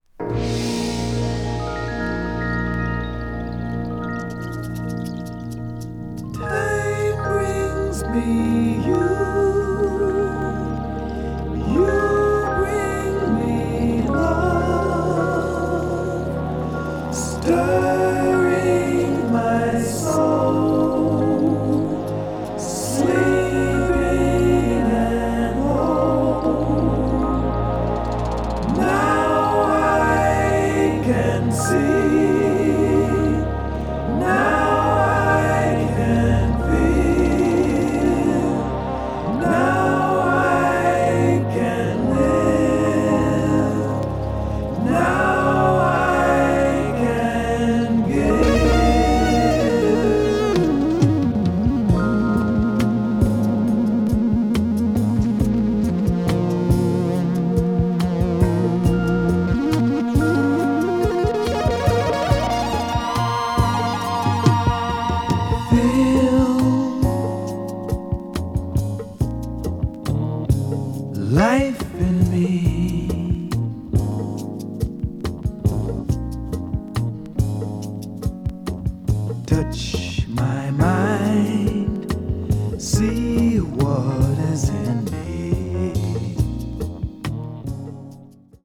crossover   fusion   jazz funk   jazz groove   mellow groove